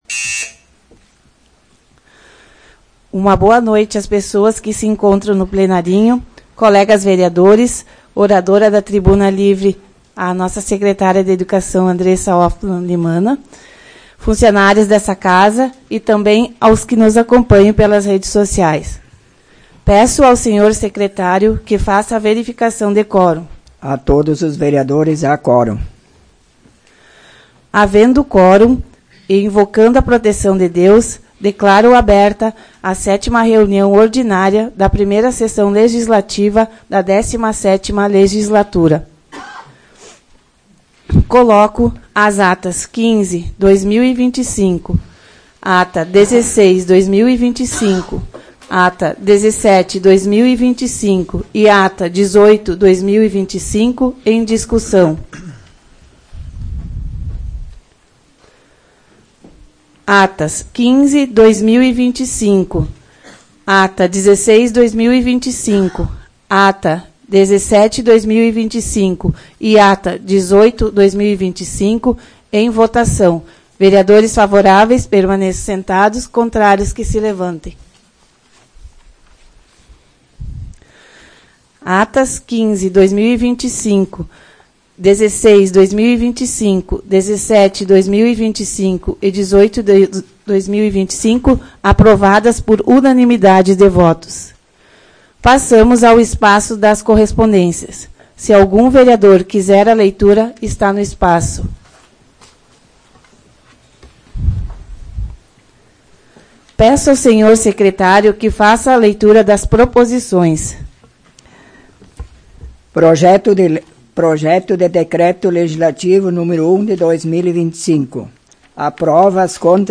Áudio da 7ª Sessão Ordinária da 17ª Legislatura, de 28 de abril de 2025